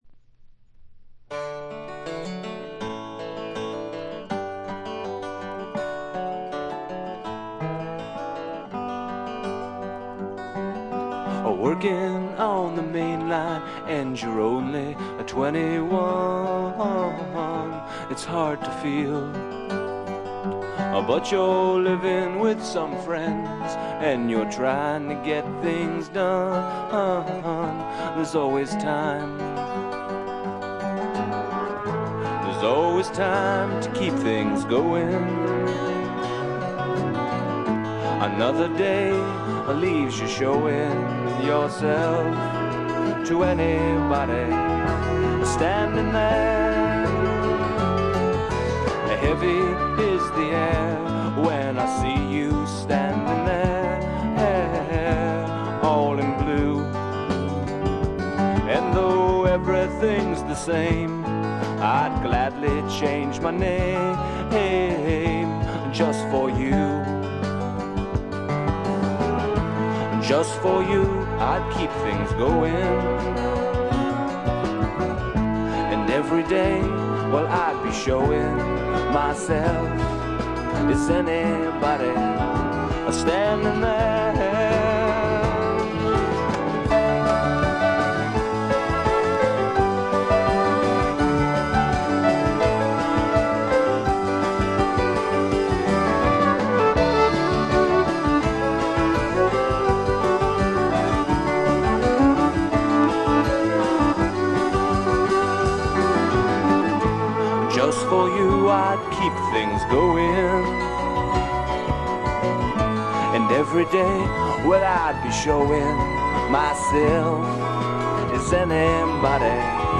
vocals and acoustic guitar
bongos
violin
piano
dobro
bass
drums.